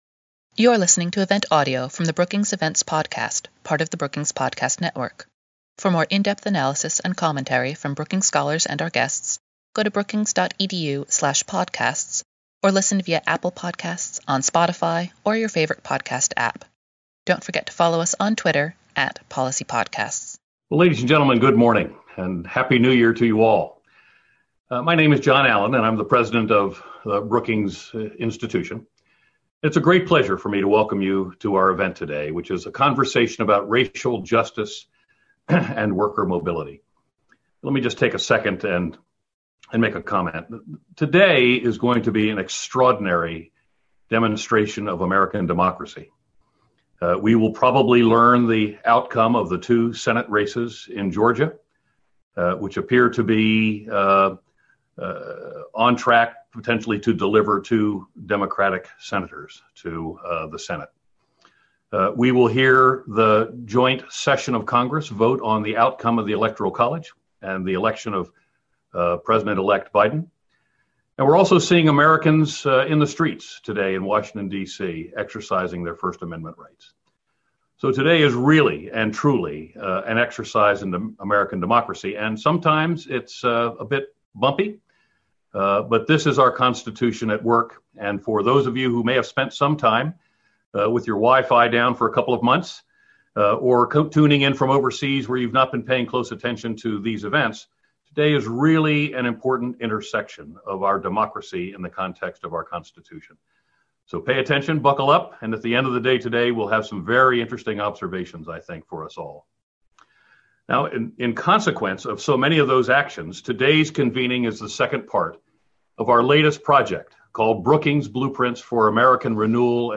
On January 6, Brookings hosted the second event in the Blueprints series, focused on addressing the acute and chronic problems facing our nation’s vulnerable workers and communities of color. A panel of scholars discussed ideas for how tax policy, housing policy, and entrepreneurship support could help narrow vast racial wealth disparities and promote a more inclusive and just recovery from the pandemic’s economic crisis.